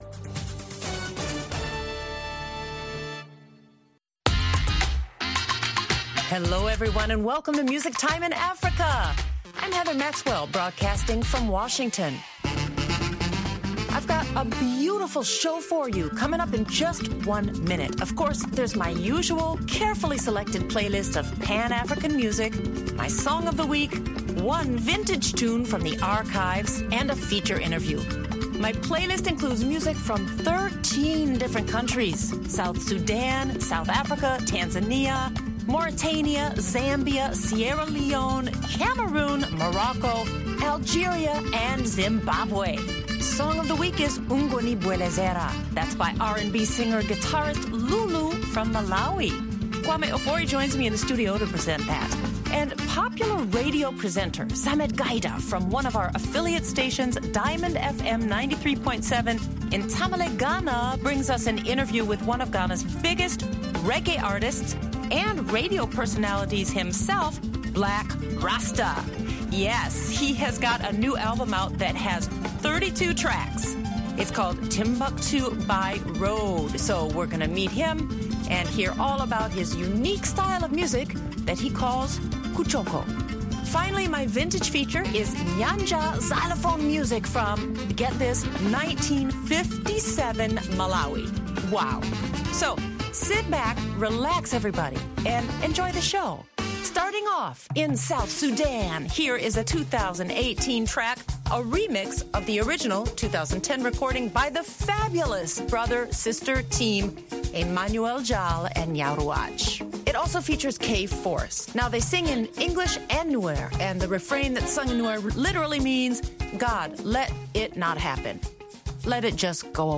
This rebroadcast from 2019 features music from South Sudan, South Africa, Tanzania, Mauritania, Zambia, Sierra Leone, Cameroon, Morocco, Algeria and Zimbabwe.
and from the Music Time in Africa Archives is Nyanja xylophone music (1957 Malawi).